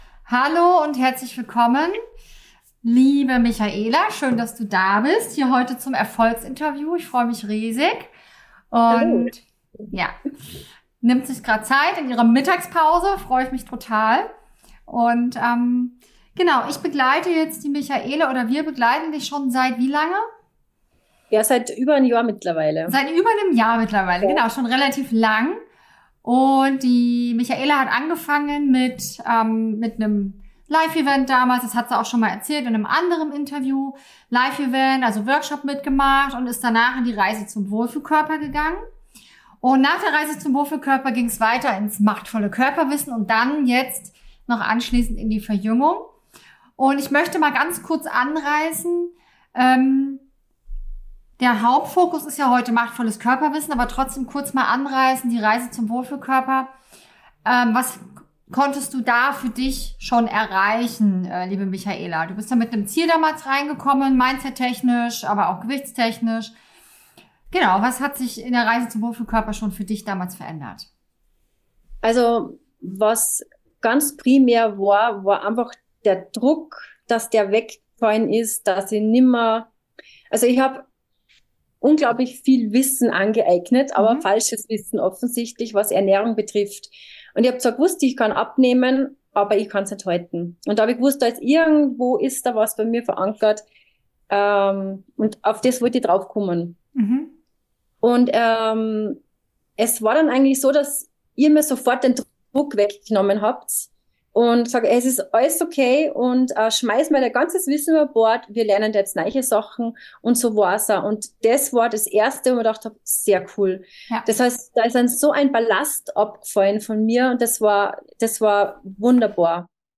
Hör dir dieses unglaubliche Erfolgsinterview an.